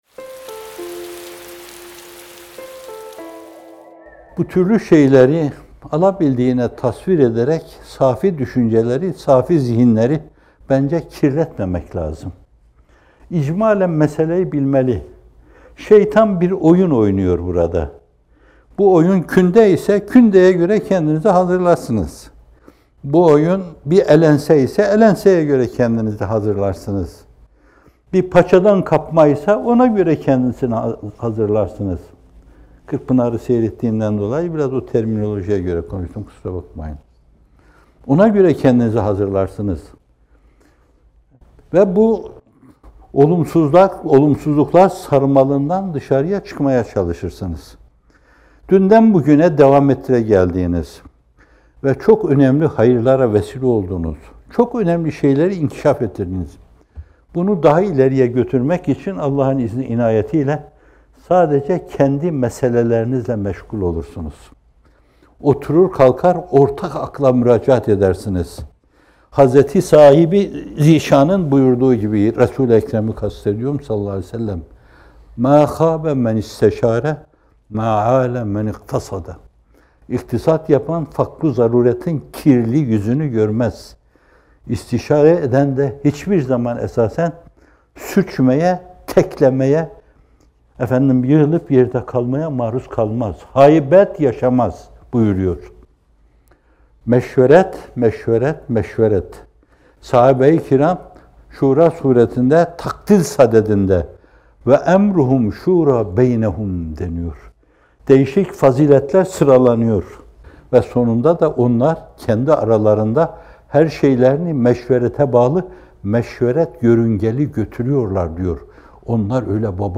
Not: Bu video, 22 Nisan 2019 tarihinde yayımlanan “Şahsiyet Yetimliğinden Kurtuluş” isimli Bamteli sohbetinden hazırlanmıştır.